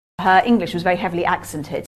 Here are all six examples currently available on YouGlish of British speakers saying accented. All six have initial stress. The first three examples have schwa in the second syllable, áksəntɪd. The last two have the vowel of DRESS in the second syllable, as in AmE, áksɛntɪd.